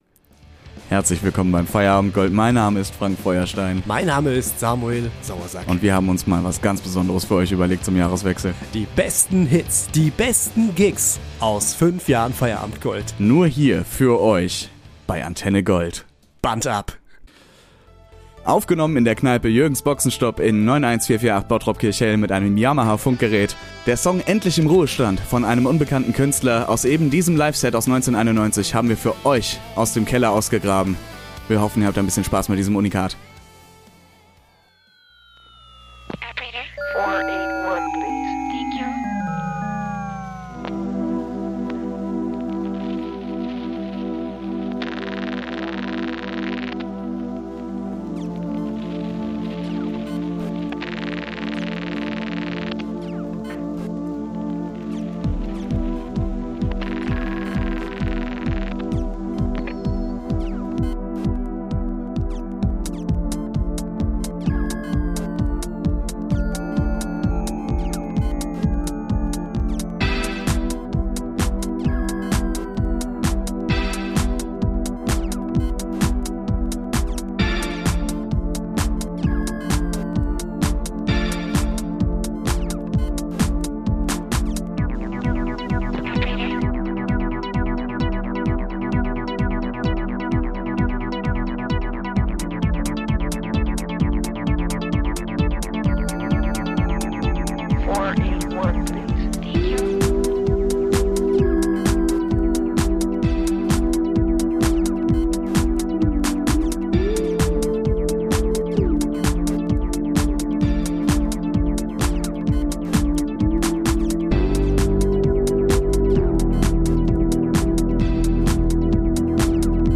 Alle oben genannten Songs in dieser Folge wurden komplett ohne KI erstellt